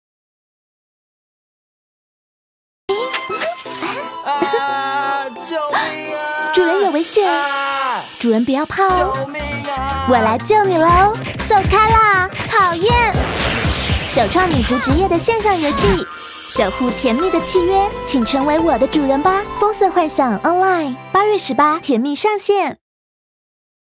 國語配音 女性配音員